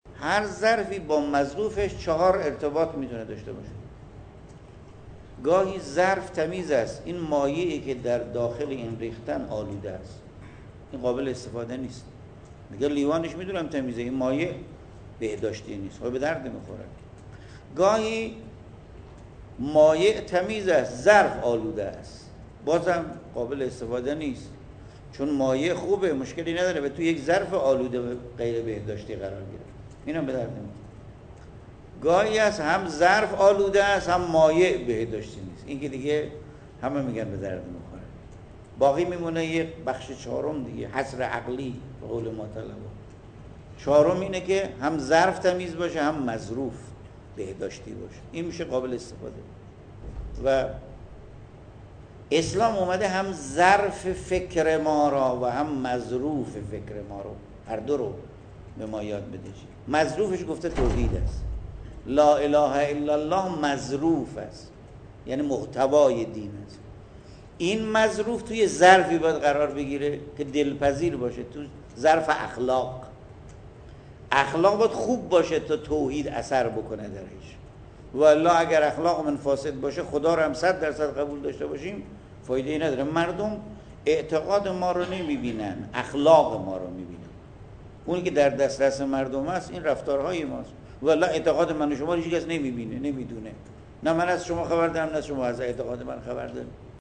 به گزارش خبرنگار خبرگزاری رسا در خراسان شمالی، حجت‌الاسلام والمسلمین ابوالقاسم یعقوبی، نماینده ولی فقیه در خراسان شمالی و امام جمعه بجنورد، امروز در جمع اعضای دوره آموزشی نسیم معرفت استانی ویژه کارکنان پایور و پیمانی نیروی انتظامی خراسان شمالی، با تأکید بر اهمیت اخلاق‌مداری در بین فعالان نیروی انتظامی، اظهار کرد: پلیس و نیروهای انتظامی در ارتباط پیوسته با مردم هستند؛ ازاین‌رو باید دارای دو ویژگی برجسته معرفت اعتقادی قوی و اخلاق با فضیلت و انسانی در مقابل هم نوعان باشند.